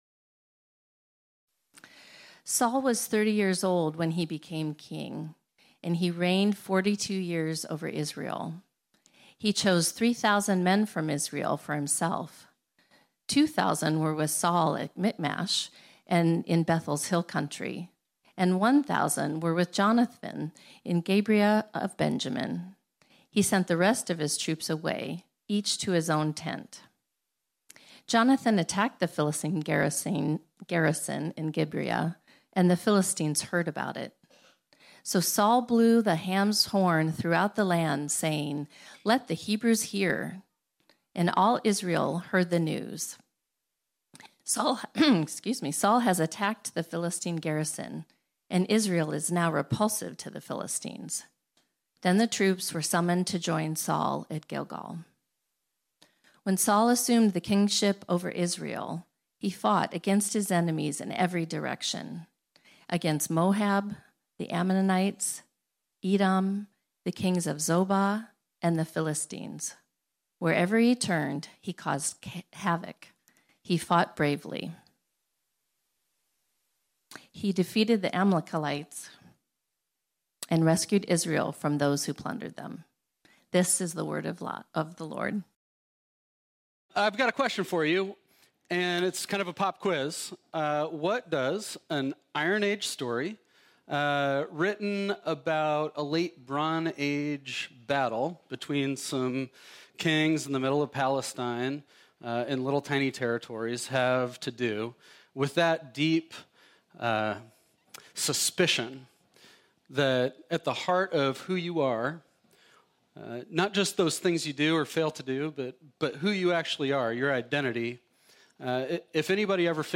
This sermon was originally preached on Sunday, May 14, 2023.